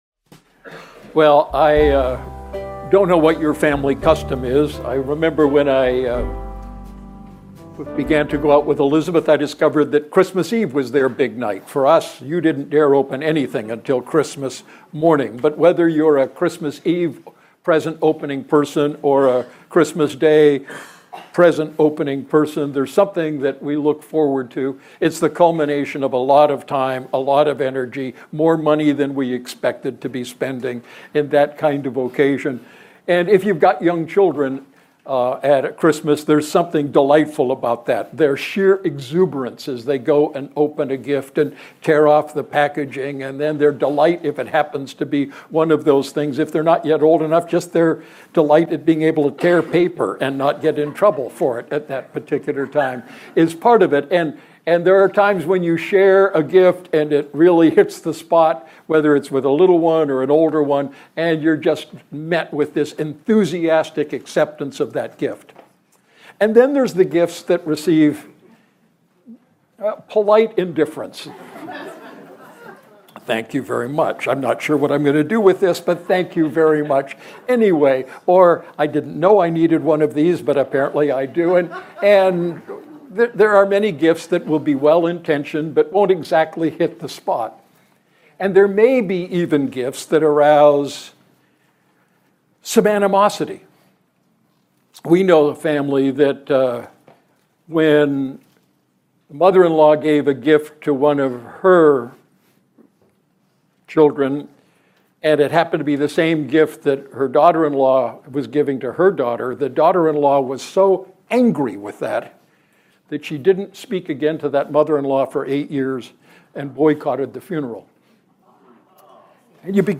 Sermon Archive | Redeemer Fellowship